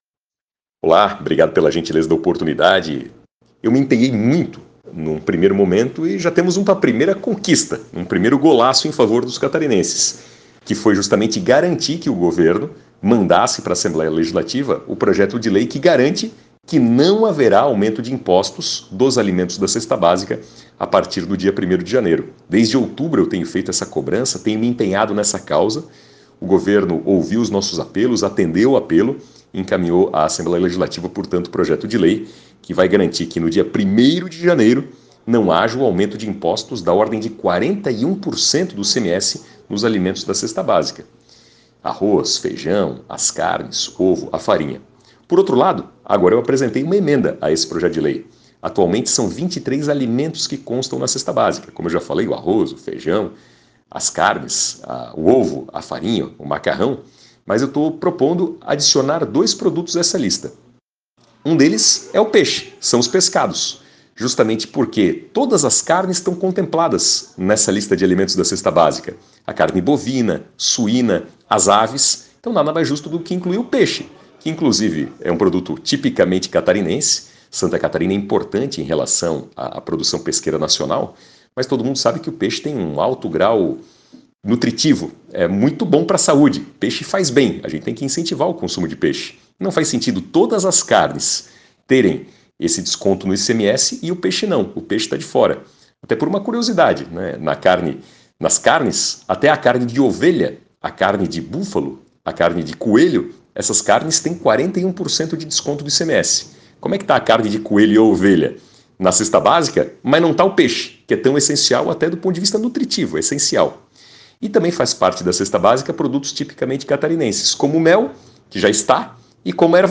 Áudio do deputado Napoleão Bernardes